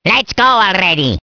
One of Waluigi's voice clips in Mario Kart DS